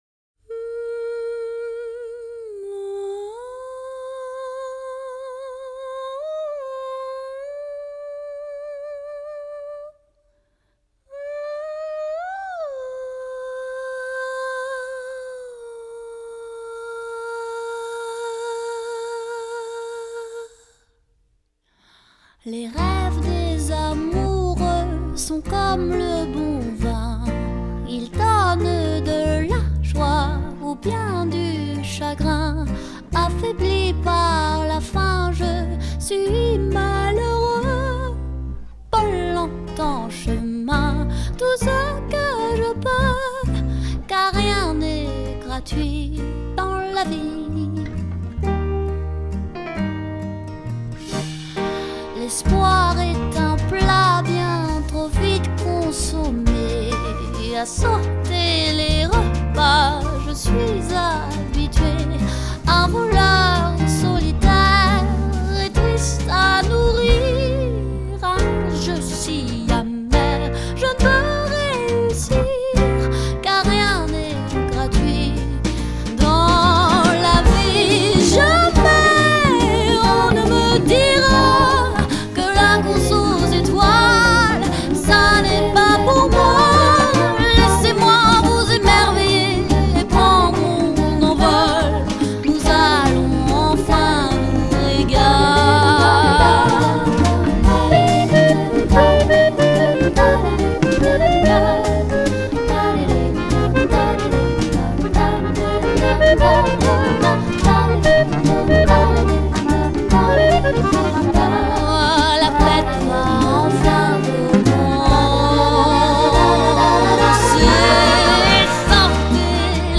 A French singer of Parisian street songs